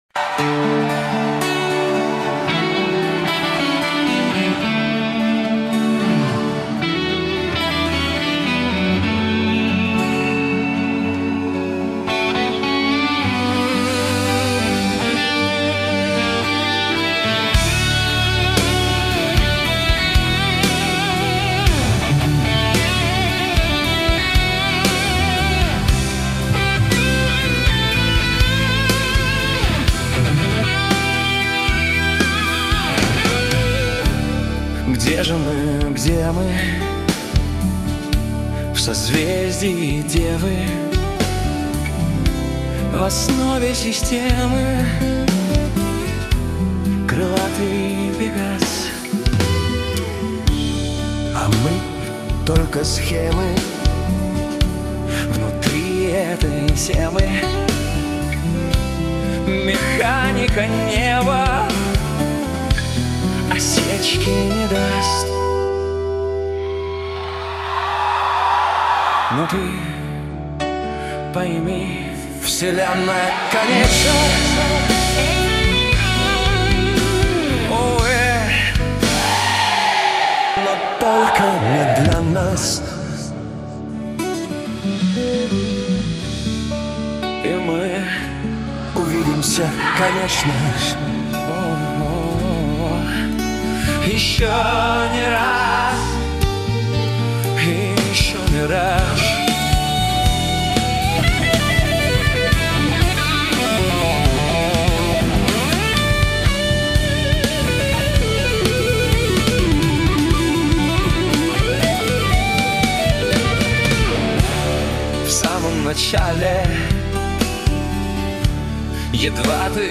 Музыкальный хостинг: /Рок
концертный вариант